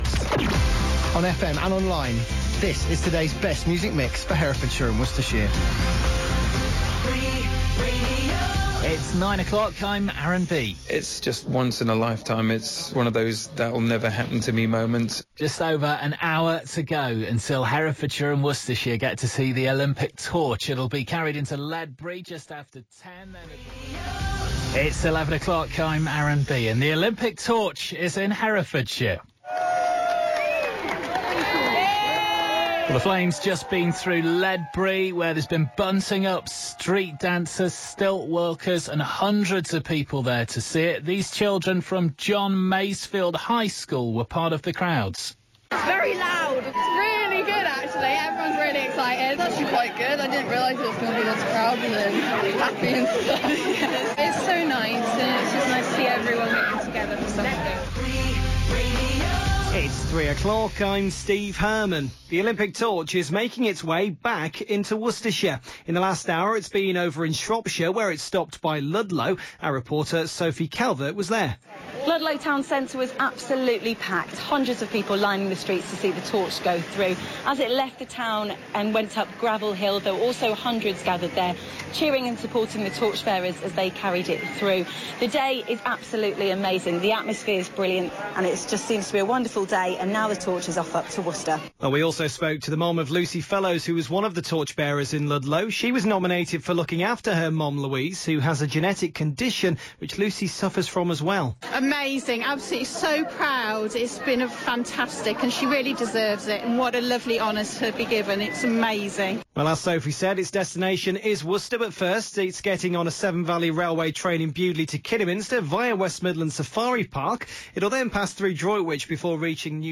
Listen back to a bit of Free Radio's News Coverage of the Herefordshire & Worcestershire Olympic Torch Relay on 24th and 25th May 2012.